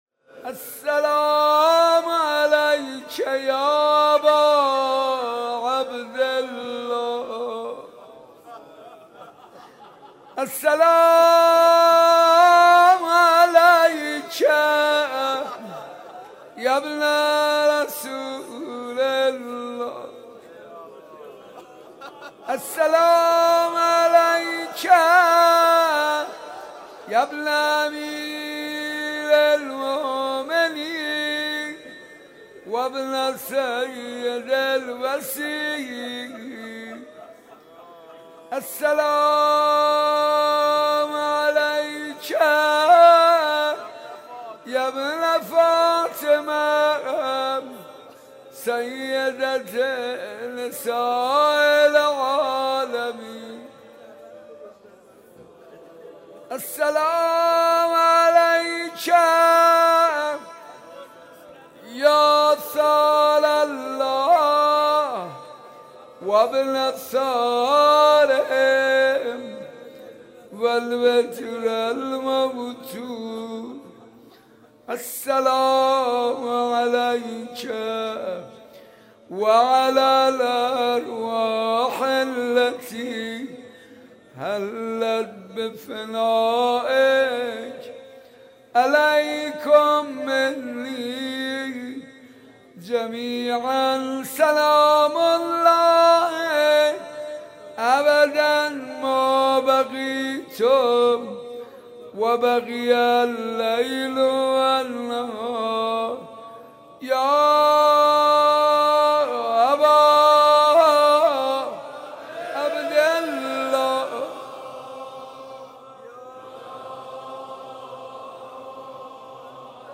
مراسم زیارت عاشورا صبح سه شنبه مورخ 1393/9/4 مصادف با ایام شهادت حضرت رقیه (سلام الله علیها) در حسینیه صنف لباس فروشان برگزار گردید.